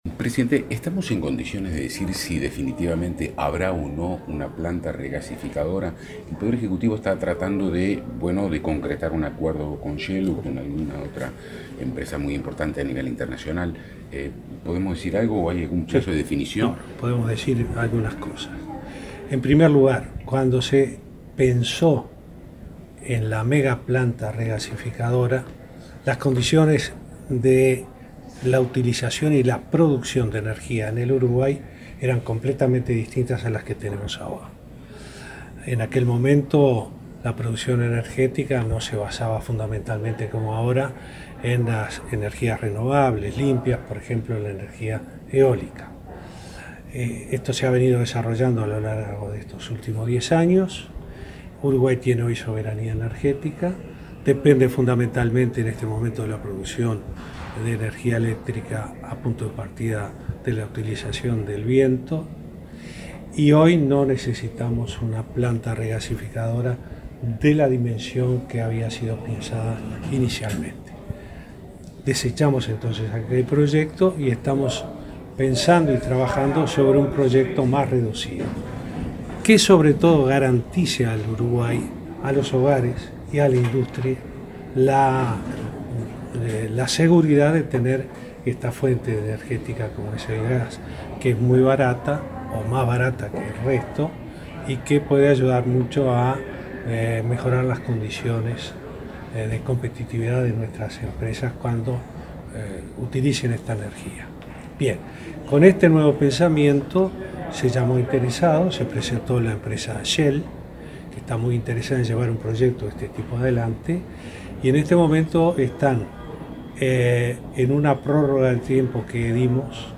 El presidente Vázquez sostuvo que el Gobierno está actuando sobre las tarifas tanto de combustible como eléctricas para bajar su costo. Recordó que se bajó el gasoil un 8 % y la electricidad para el sector lácteo y arrocero un 15 %. En diálogo con la prensa en Nueva York, habló de la evolución positiva de Ancap, del acuerdo con UPM para la segunda pastera y de la decisión sobre la regasificadora que se tomará sobre fin de año.